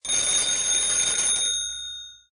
telephone_ring.ogg